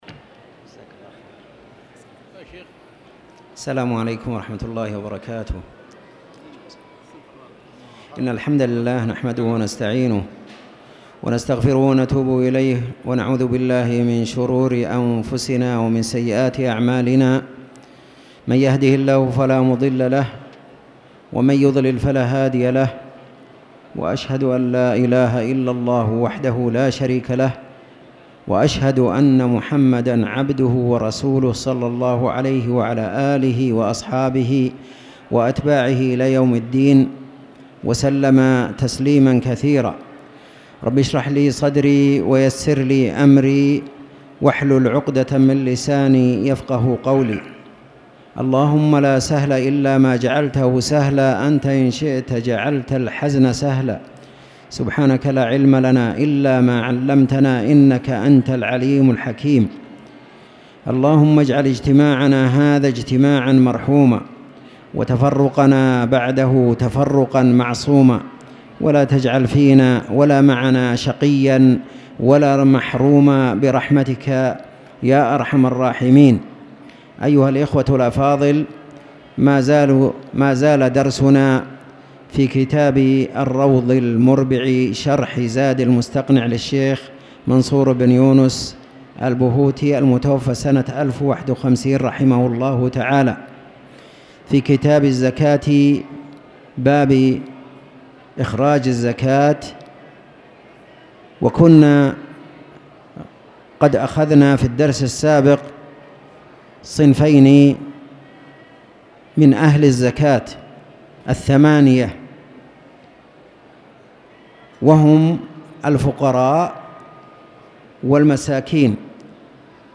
تاريخ النشر ٢١ صفر ١٤٣٨ هـ المكان: المسجد الحرام الشيخ